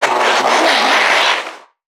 NPC_Creatures_Vocalisations_Infected [30].wav